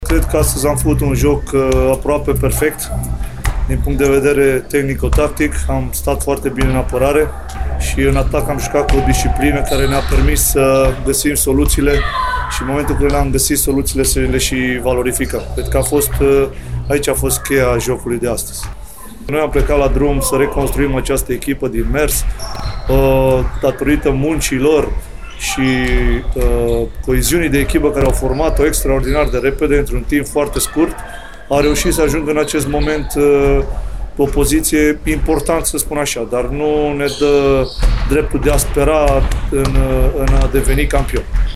La declarațiile de după meci